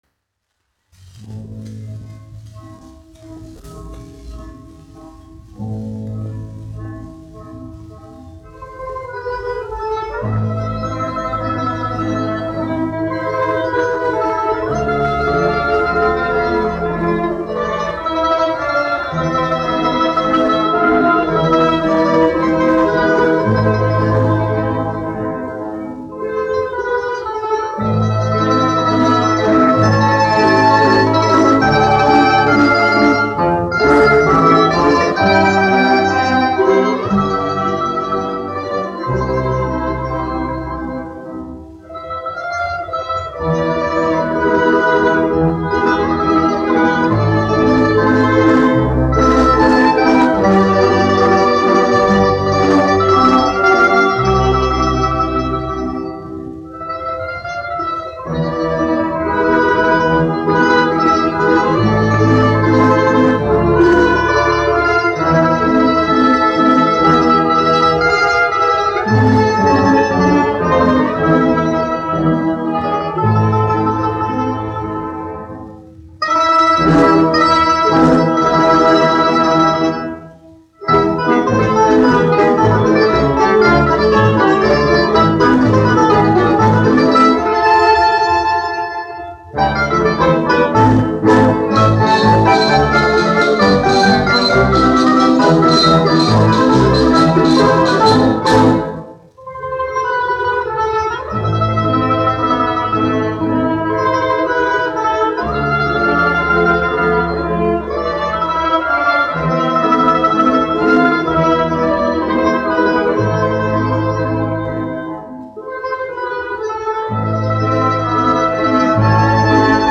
1 skpl. : analogs, 78 apgr/min, mono ; 25 cm
Balalaiku orķestra mūzika
Skaņuplate